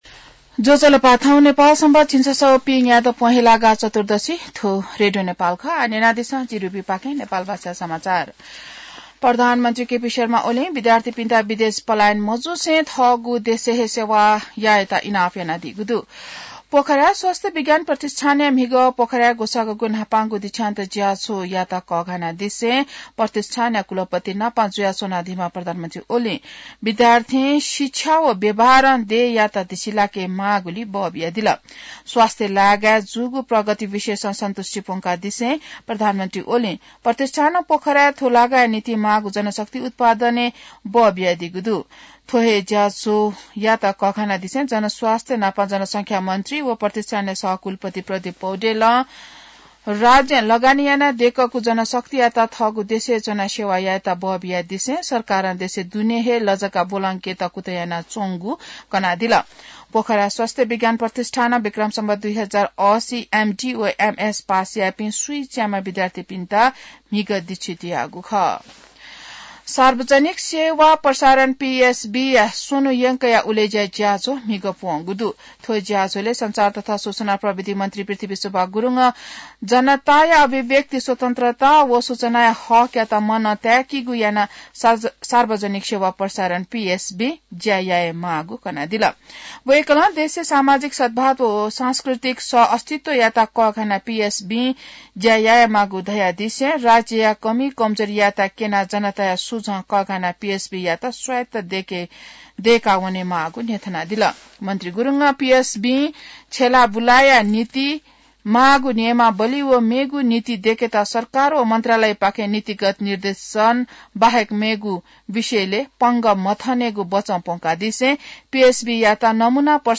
नेपाल भाषामा समाचार : ५ माघ , २०८१